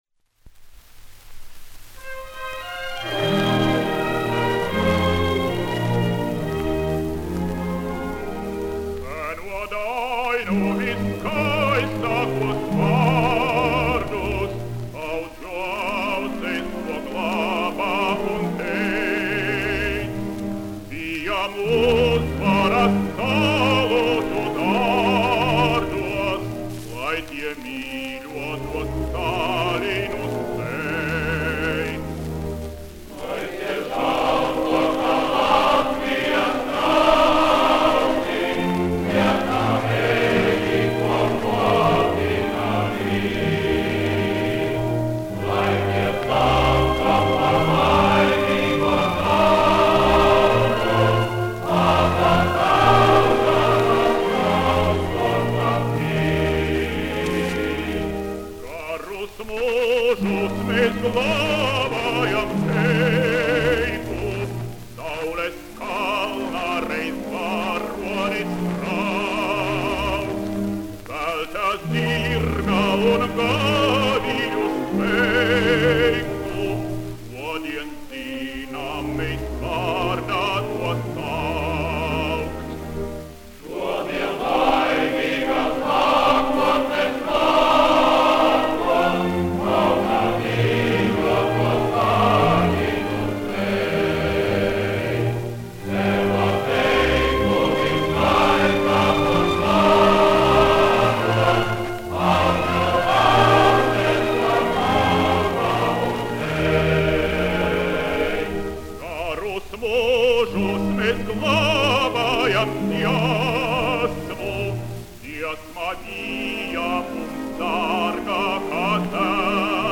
Здравица на латышском языке от классика советской музыки.